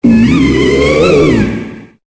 Cri de Banshitrouye dans Pokémon Épée et Bouclier.